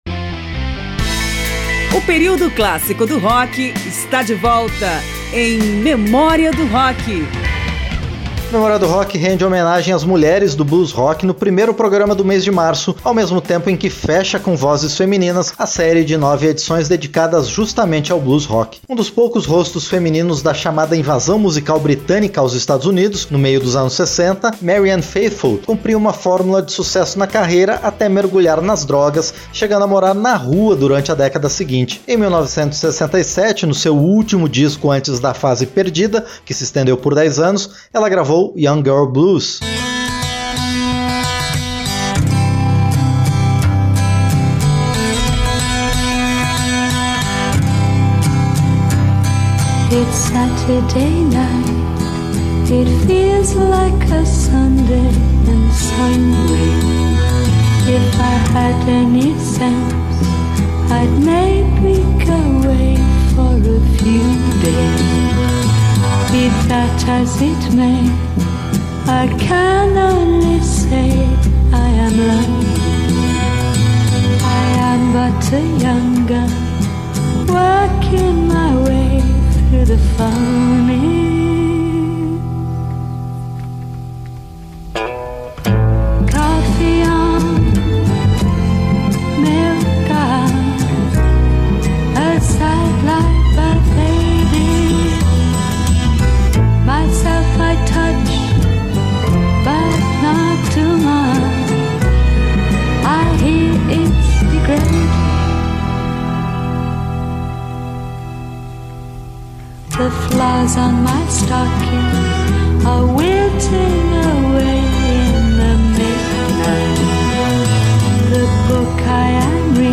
Pesquisa, texto e apresentação